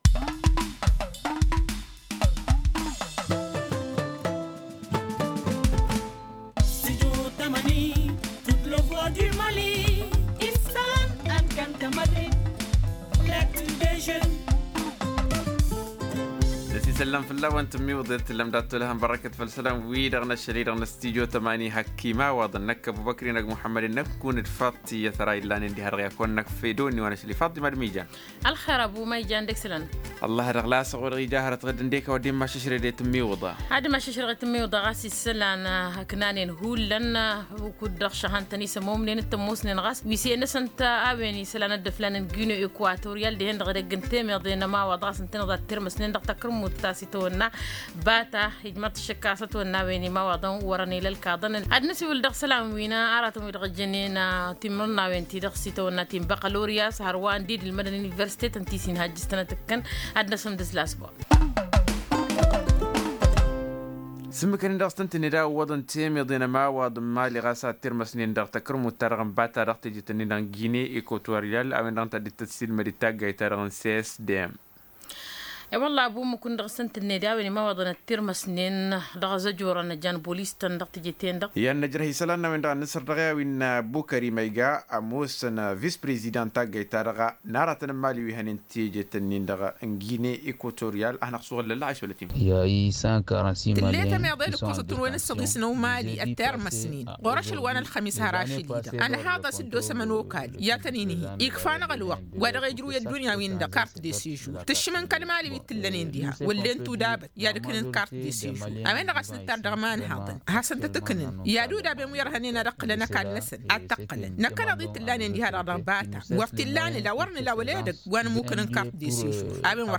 Plus d’une centaine de jeunes maliens détenus dans des prisons à Bata en Guinée Équatoriale. Témoignage dans ce journal.